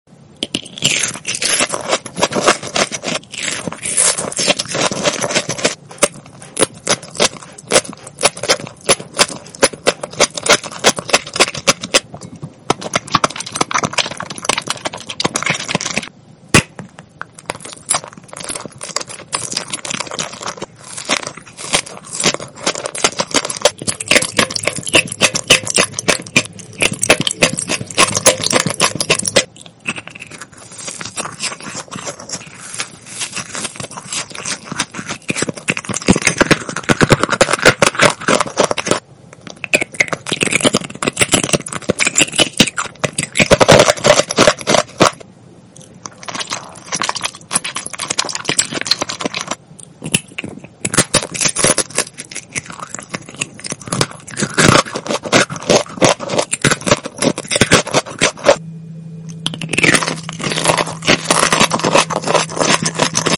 ASMR EMOJI FOOD kohakuto sprinkle sound effects free download
ASMR EMOJI FOOD kohakuto sprinkle honey jelly marshmallow sea grape chocolate mukbang eating sounds